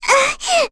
Lilia-Vox_Damage_02.wav